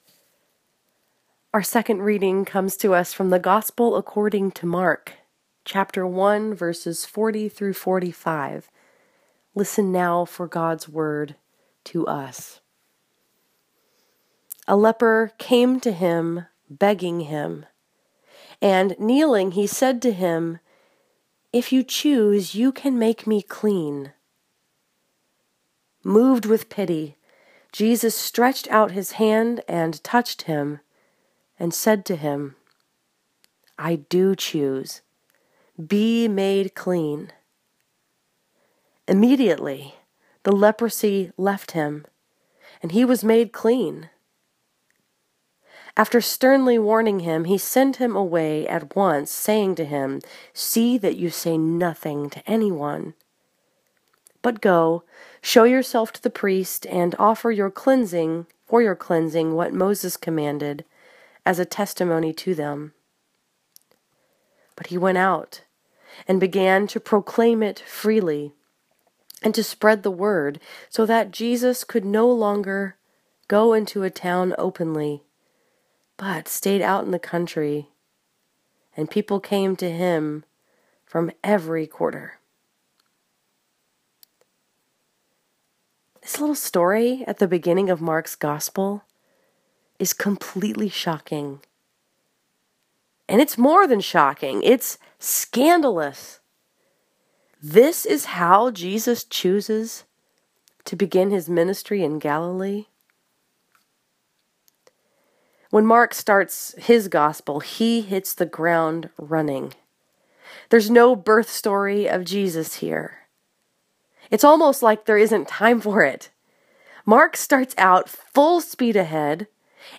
This sermon was preached at St. Andrew’s Presbyterian Church in Dearborn Heights, Michigan and was focused upon Mark 1:40-45.